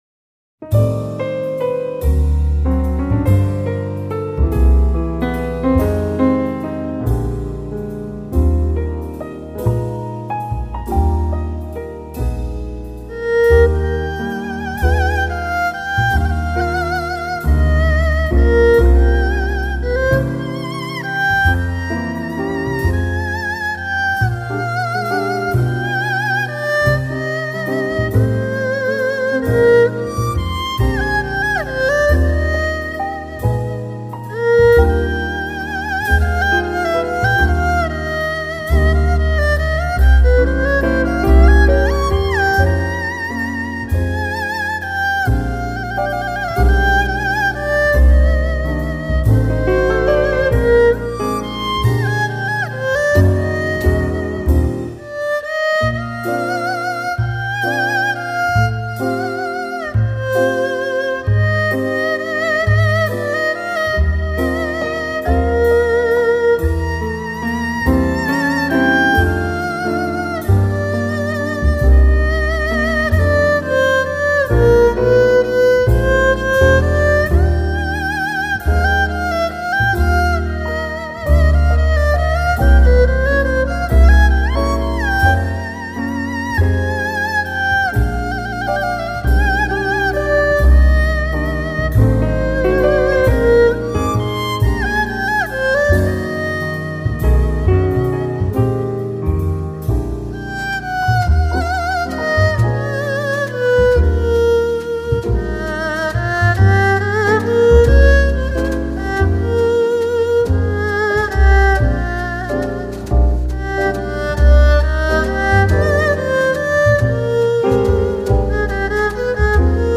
当东洋的古典乐器和西洋的古典相遇，会开辟怎样的新境界呢？